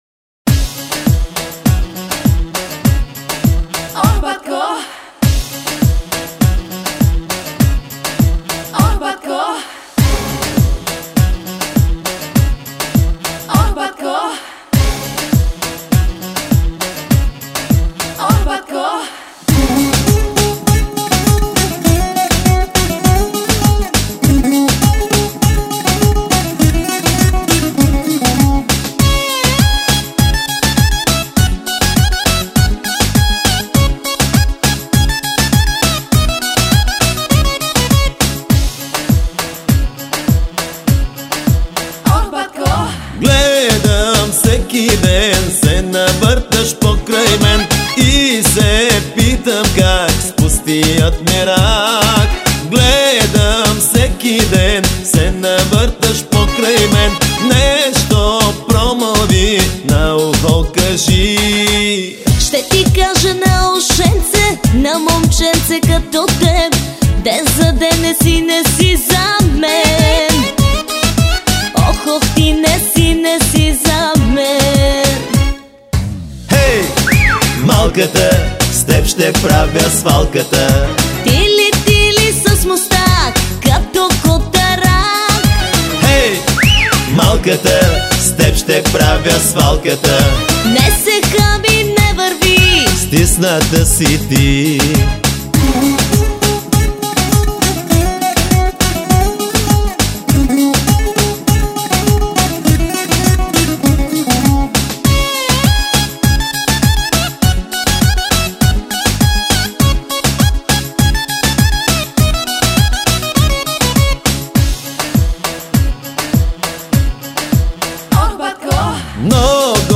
Bolgarskaja_Veselaja_pesnja_zazhigatelnaja_.mp3